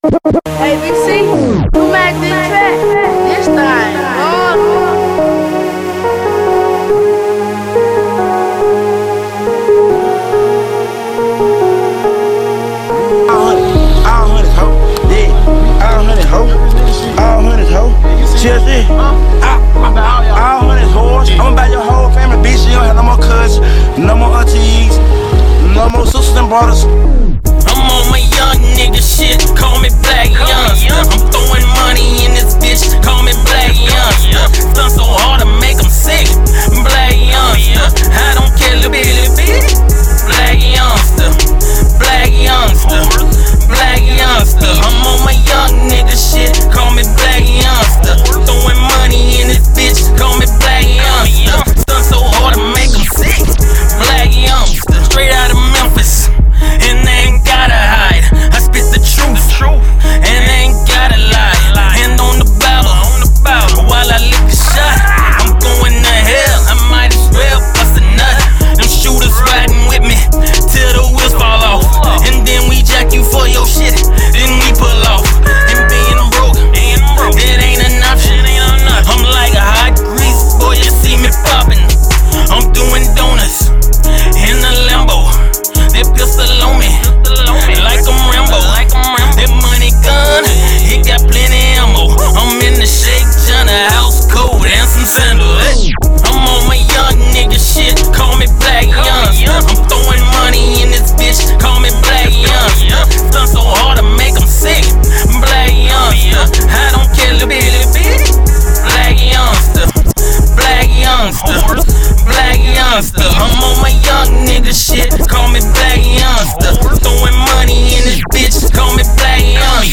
Hiphop
Uptempo club hit